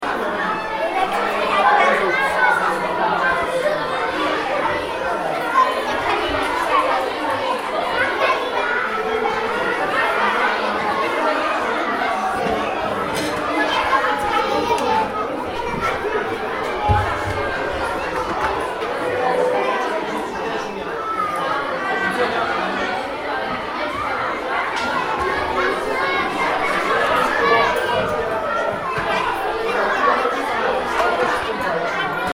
Звуки школьной перемены
Шум в классе на перемене